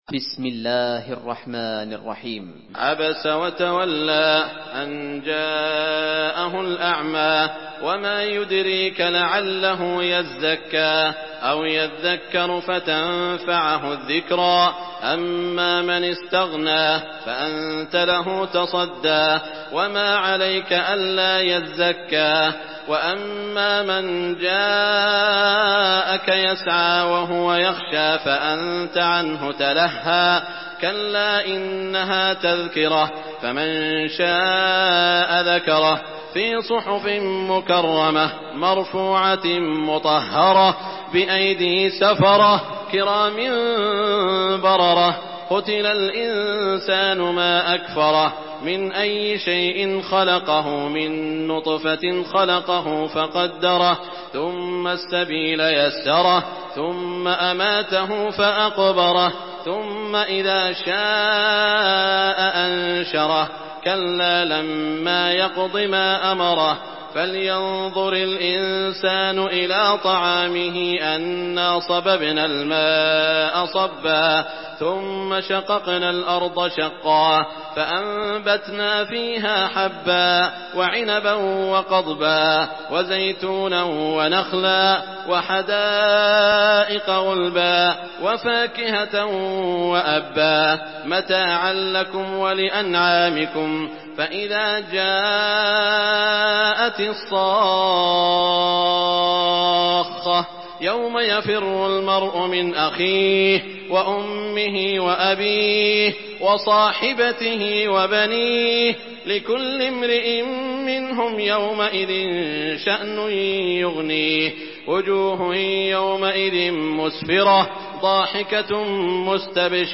Surah Abese MP3 by Saud Al Shuraim in Hafs An Asim narration.
Murattal Hafs An Asim